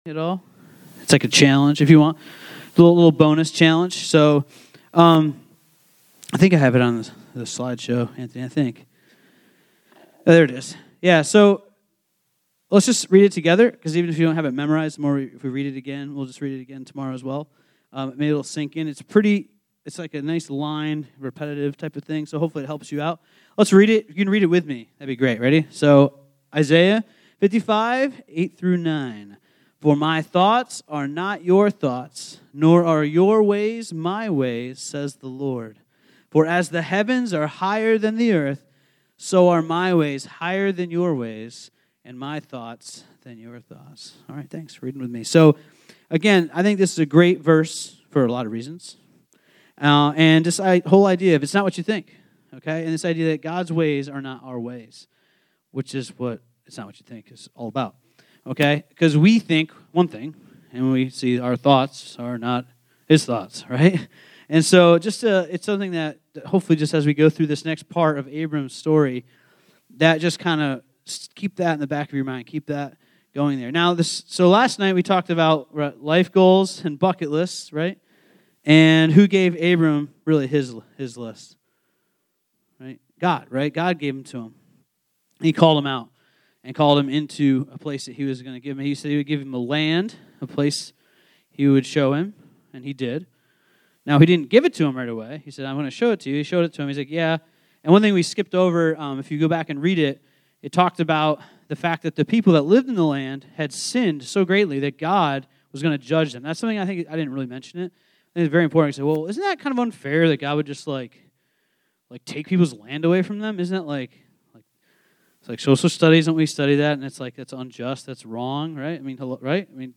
Rock Mountain Bible Camp Traps & Promises - Genesis 16 Play Episode Pause Episode Mute/Unmute Episode Rewind 10 Seconds 1x Fast Forward 10 seconds 00:00 / 40 minutes 28 seconds Subscribe Share RSS Feed Share Link Embed